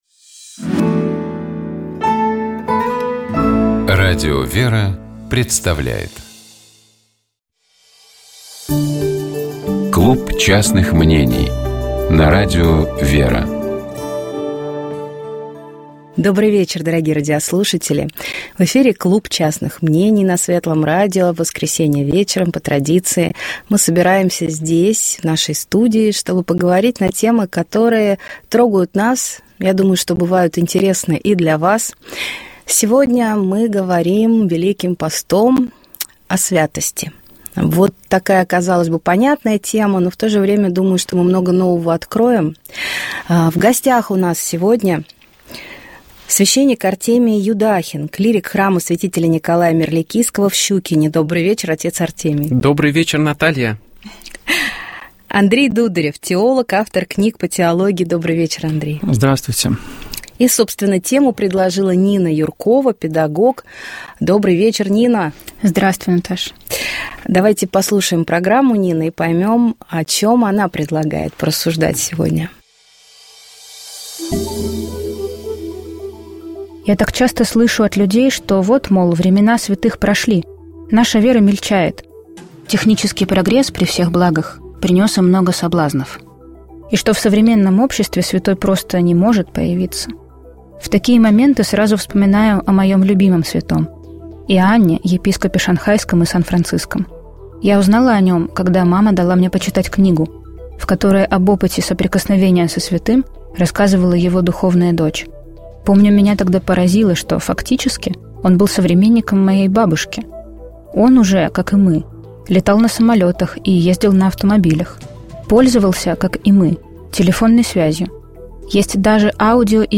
Вот такой сюжет у кинофильма «Письма Богу» — фрагмент из него мы только что послушали.